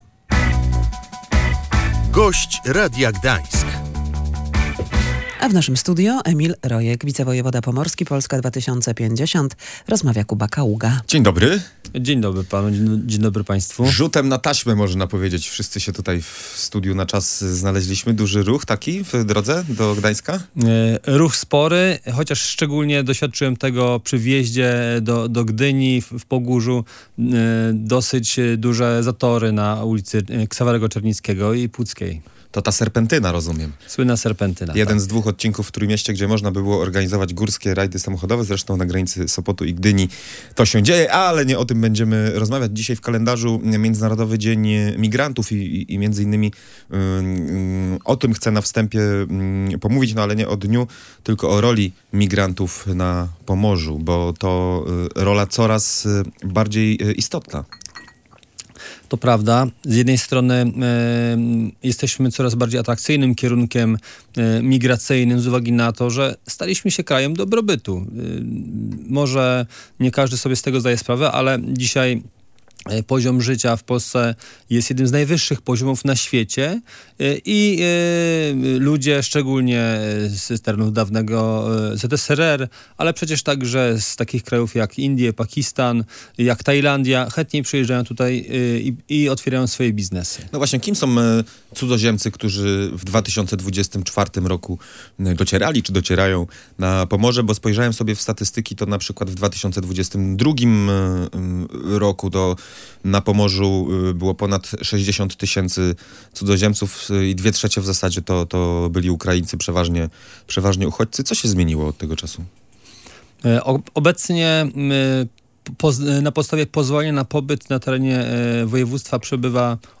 Około 115 tysięcy migrantów przebywa obecnie na Pomorzu. Jesteśmy coraz bardziej atrakcyjnym krajem dla cudzoziemców, ale sporo osób przyjeżdża tutaj do pracy – mówił w Radiu Gdańsk wicewojewoda pomorski Emil Rojek.